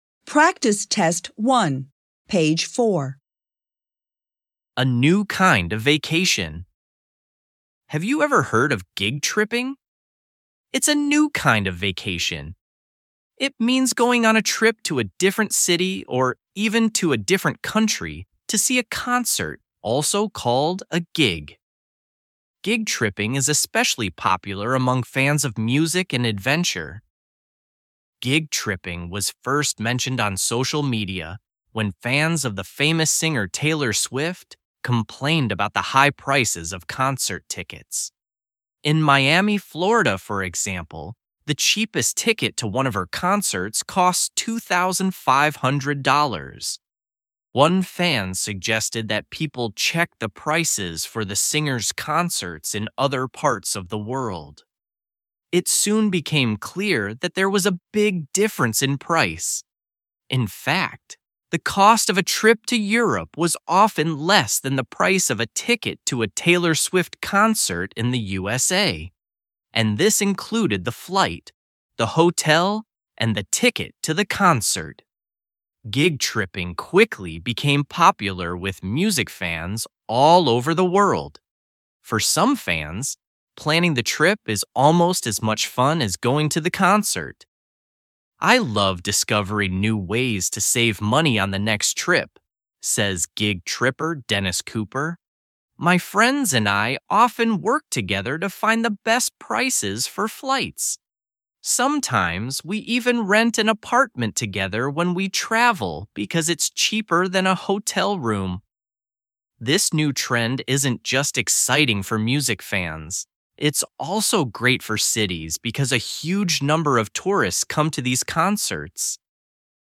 ECB Online offers you audio recordings of the reading texts from your coursebook to help you understand and enjoy your lessons.